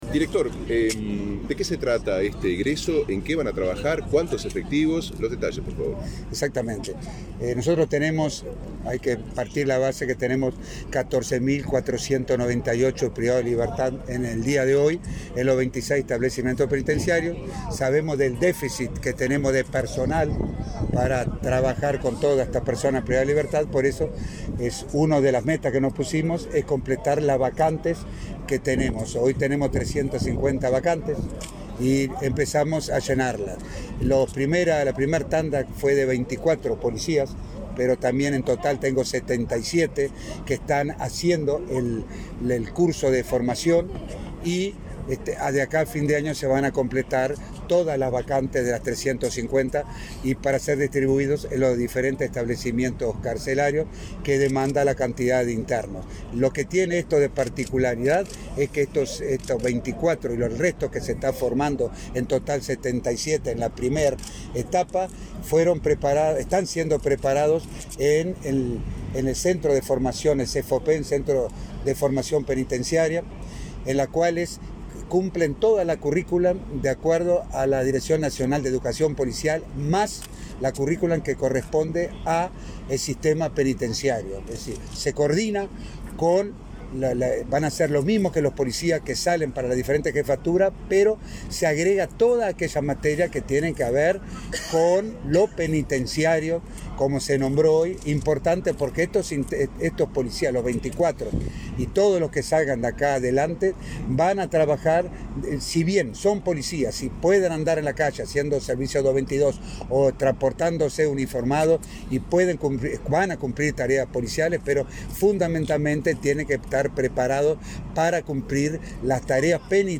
Declaraciones a la prensa del director del INR, Luis Mendoza